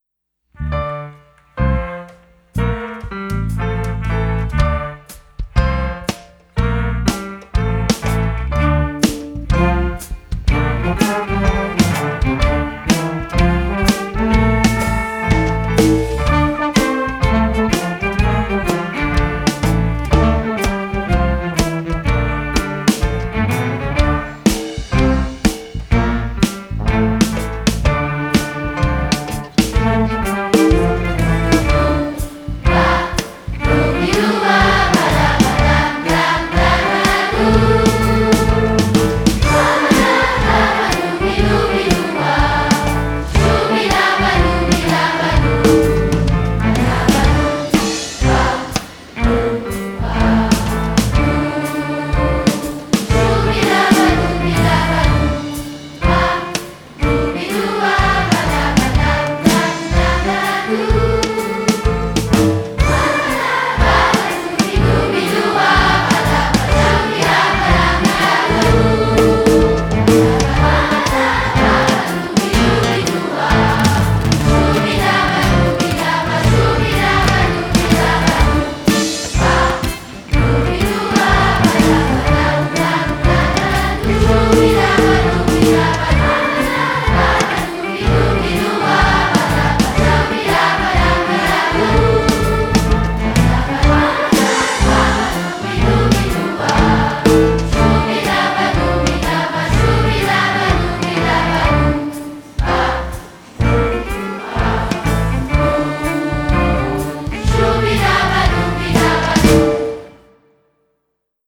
Vous trouverez ci-dessous en téléchargement les 12 chants (fichier zip comprenant la version chantée + playback + partition pdf)
05 - Doe ba (chant uniquement)